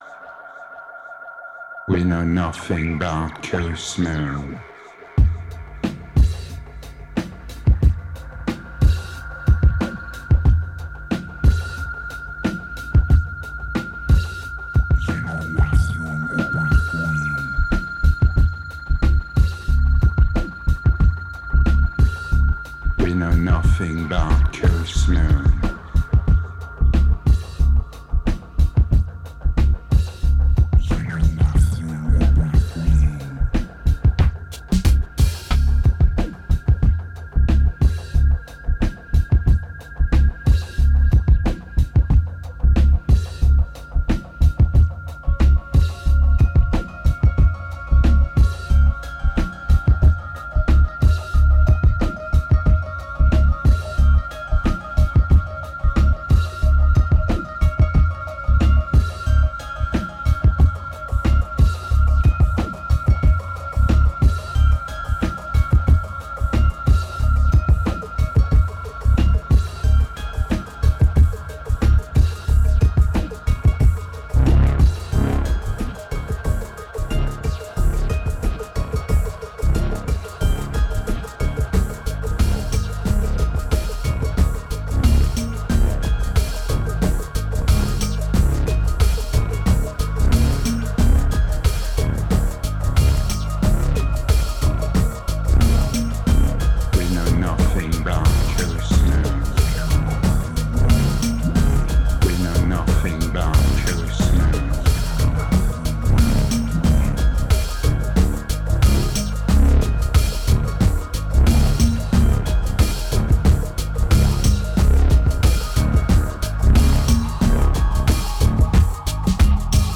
2069📈 - -29%🤔 - 91BPM🔊 - 2010-10-16📅 - -187🌟